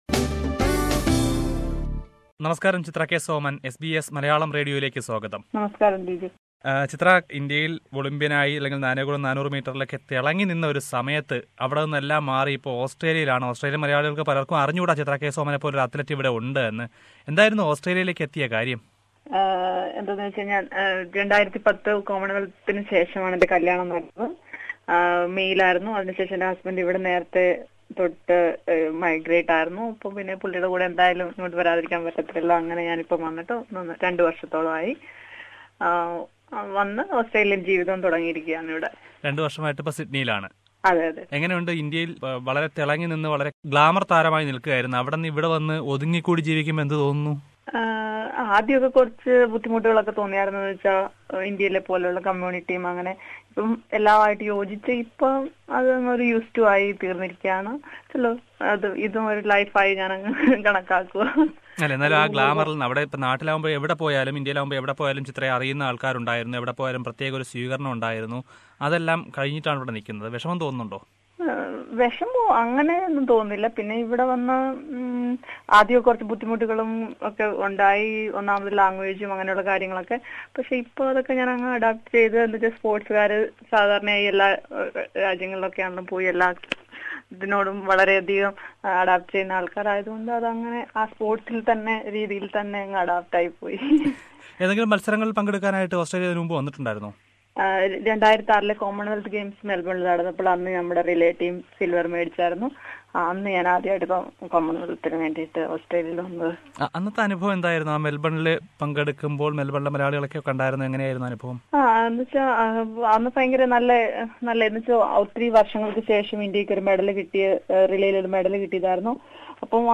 Let us listen to her words about life in Australia and her furture plans in athletics - First Part of the Interview..